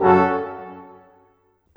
Rock-Pop 01 Brass 06.wav